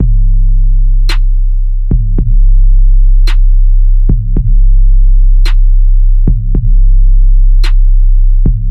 失落的鼓声
Tag: 110 bpm Hip Hop Loops Drum Loops 1.47 MB wav Key : Unknown